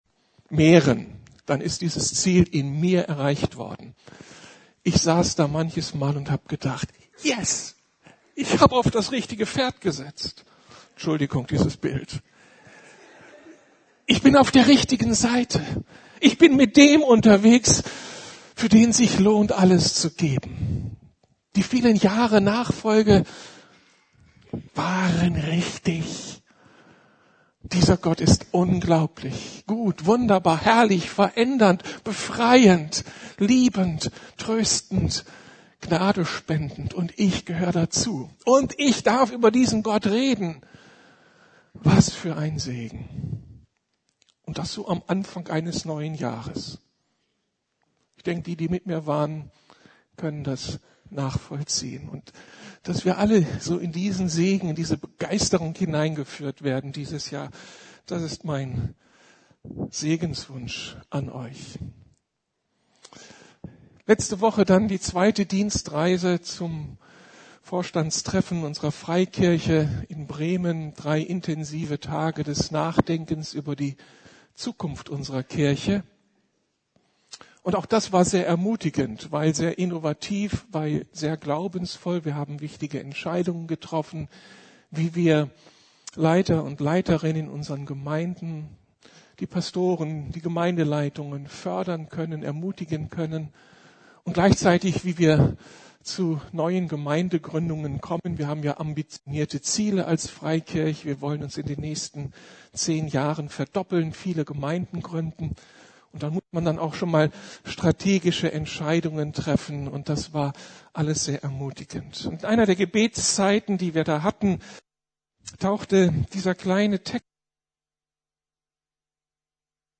Weite meine Grenzen! ~ Predigten der LUKAS GEMEINDE Podcast